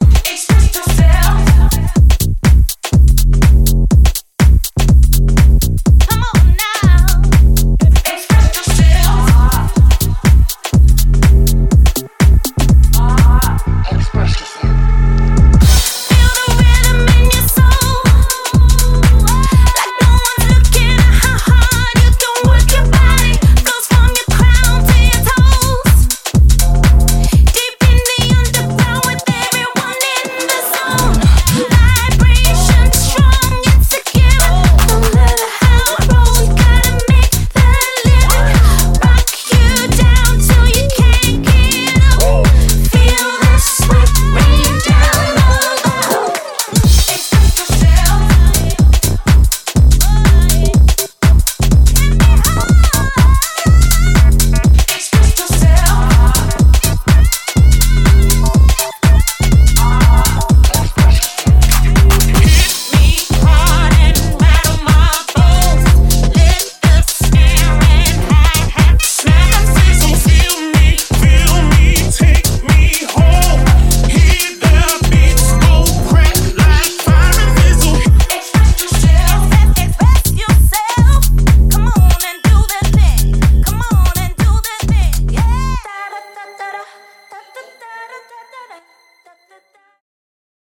ジャンル(スタイル) HOUSE